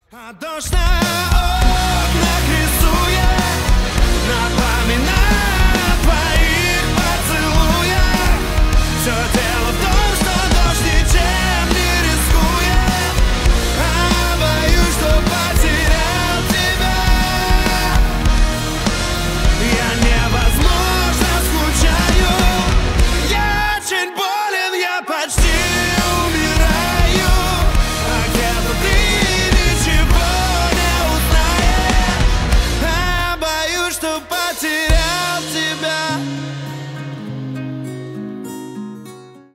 • Качество: 320, Stereo
поп
мужской вокал
Cover
электрогитара
романтичные